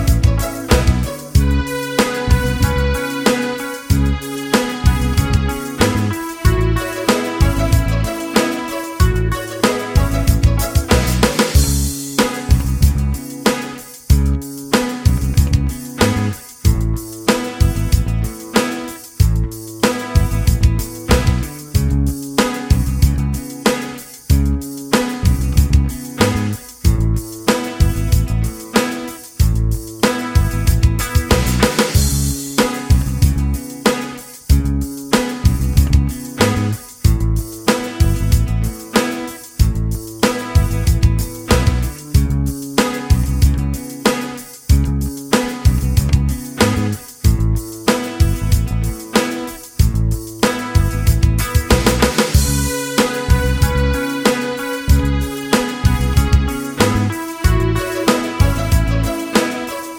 No Backing Vocals Indie / Alternative 4:10 Buy £1.50